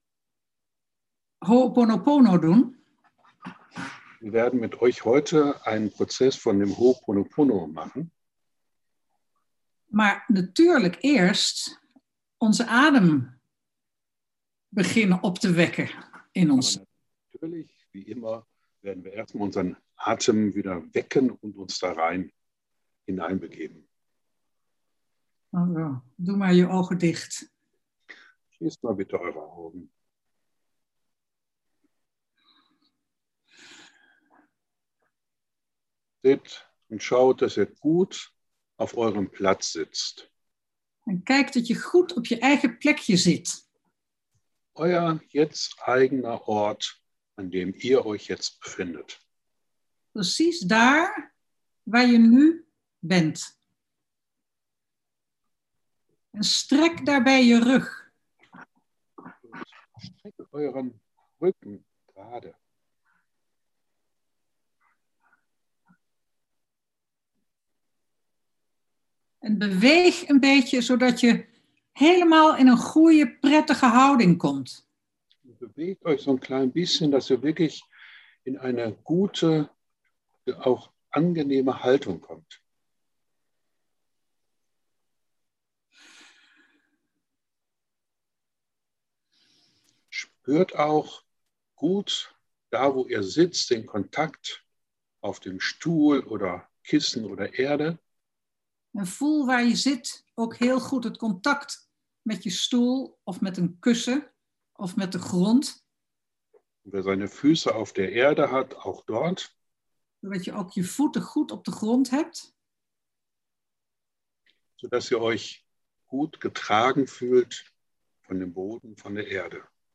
Atemmeditation zum Herunterladen als mp3-Datei